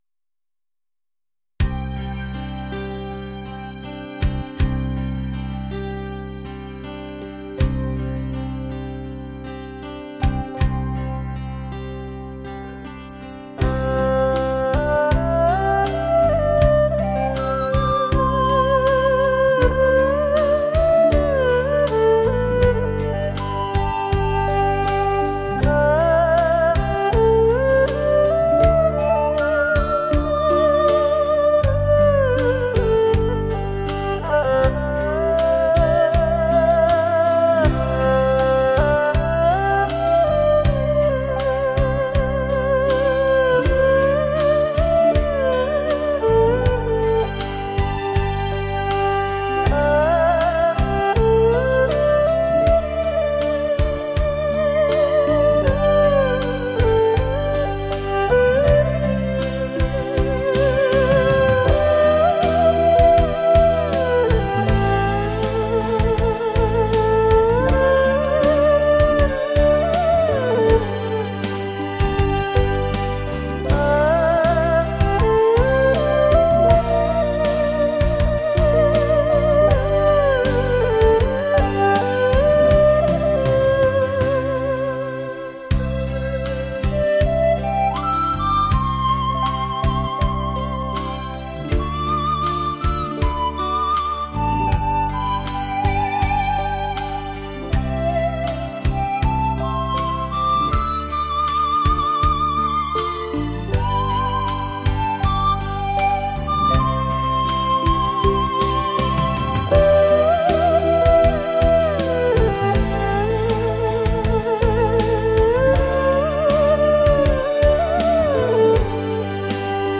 二胡恋曲
二胡演奏出流行通俗的佳作，让您在聆听中放松心情，是你不容错过的珍藏。